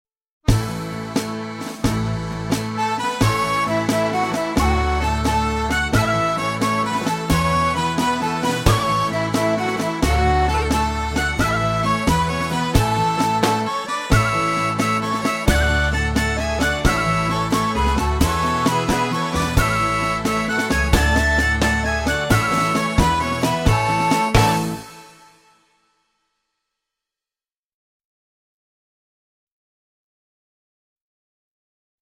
VS The Hundred Pipers (backing track)